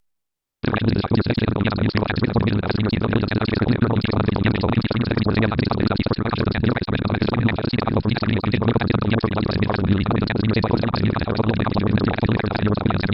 This is what my screen reader sounds like when reading this section:
To most ears, synthetic speech at 800 WPM sounds like a meaningless stream of robot chatter.
I use Eloquence, which generates speech by using formant synthesis.
screen reader 800 WPM demo.flac